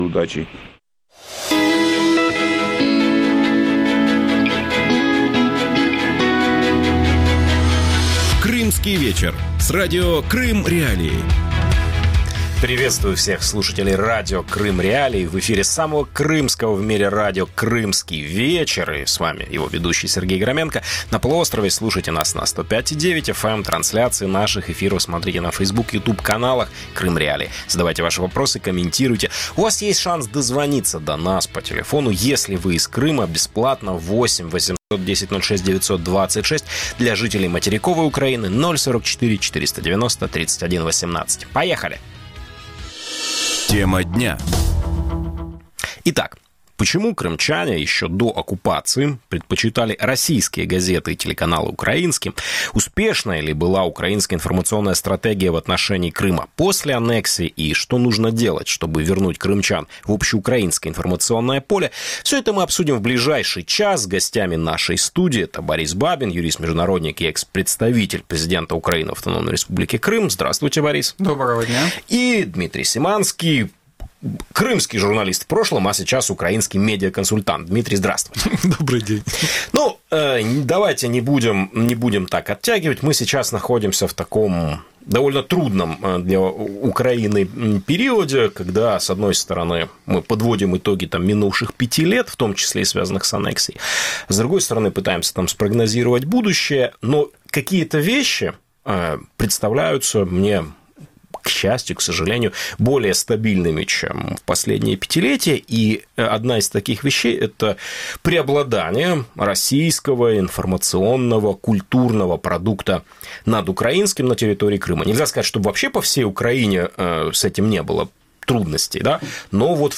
Почему крымчане еще до оккупации предпочитали российские газеты и телеканалы украинским? Успешной ли была украинская информационная стратегия в отношении Крыма после аннексии? И что нужно делать, чтобы вернуть крымчан в общеукраинское информационное поле? Гости в студии